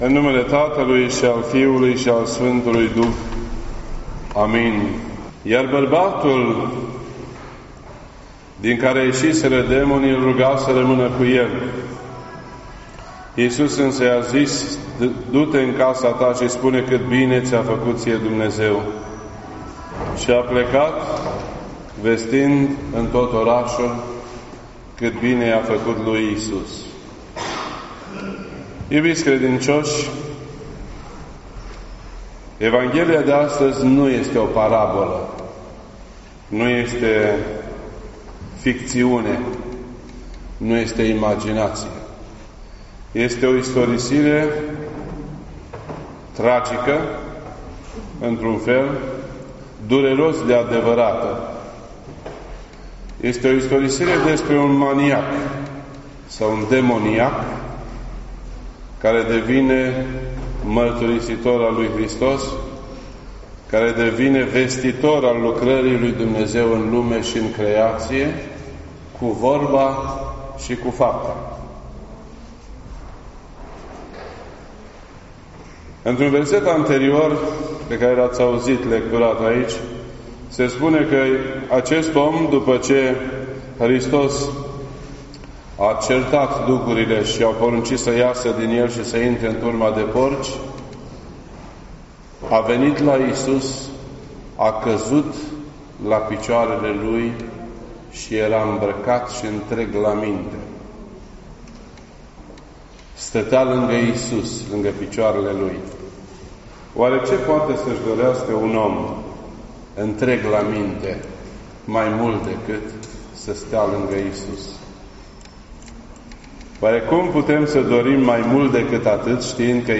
This entry was posted on Sunday, October 20th, 2019 at 4:23 PM and is filed under Predici ortodoxe in format audio.